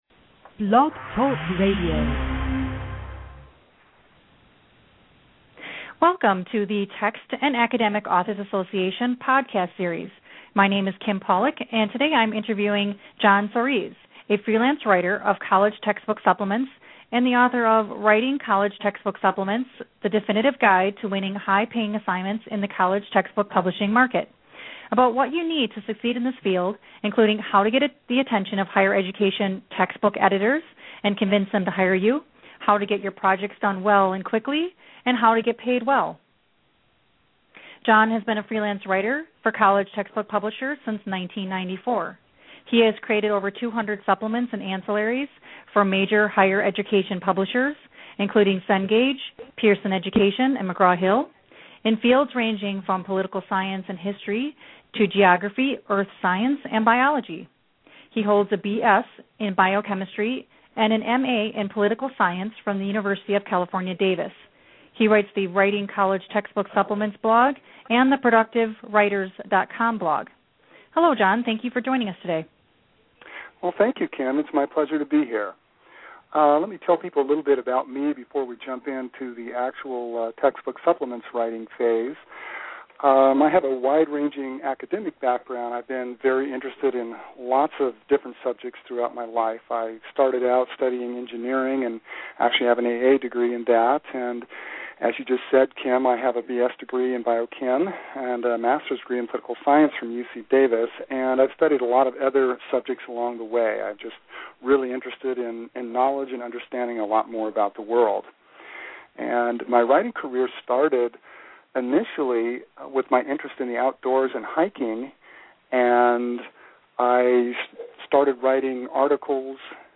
What I Discuss in the Interview